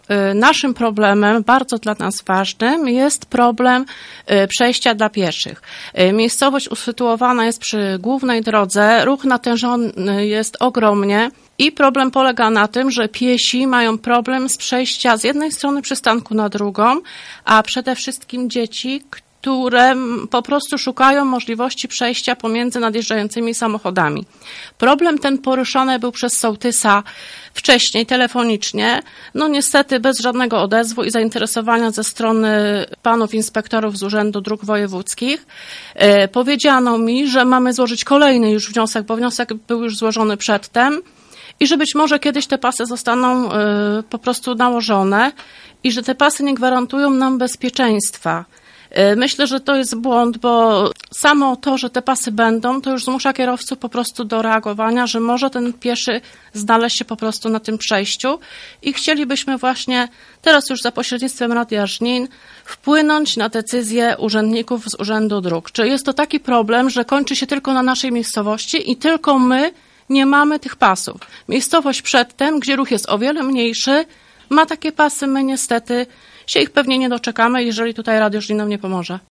mówiła jedna z mieszkanek Pturka.